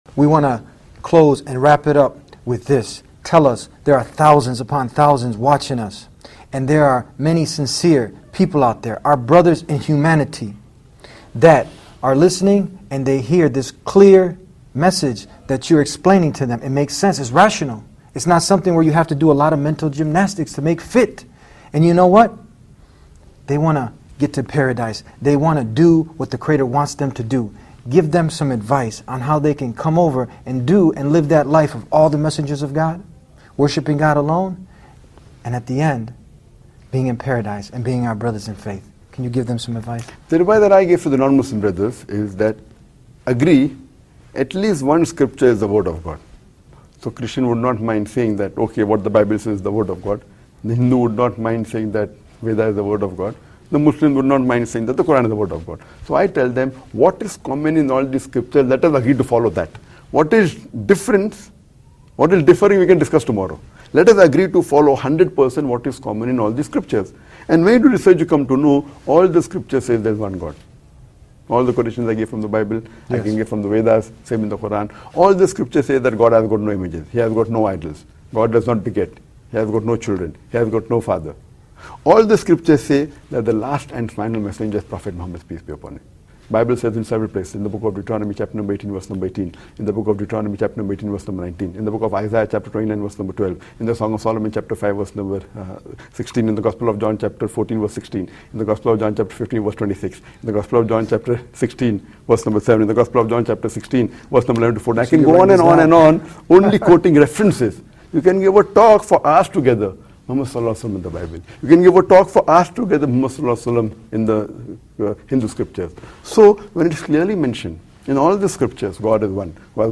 Dr. Zakir Naik the world famous Scholar on Islam and comparative religion gives some advice for Non-Muslims in this clip which I took from one of the recent episodes of The Deen Show.